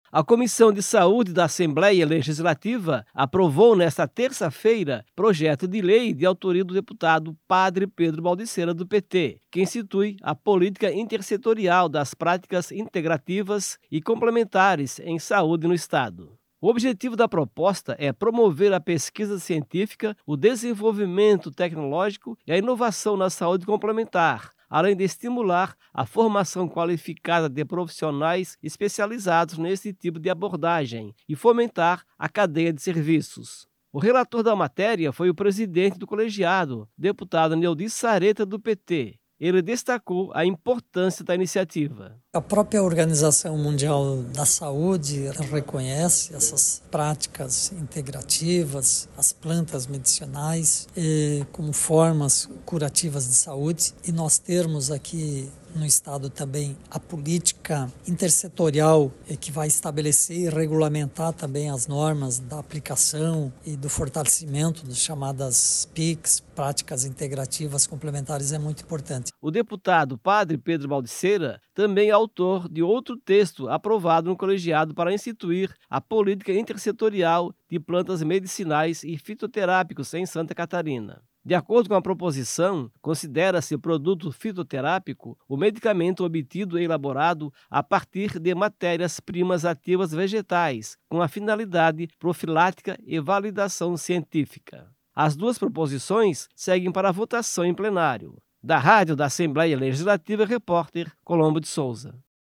Entrevista com:
- deputado Neodi Saretta (PT).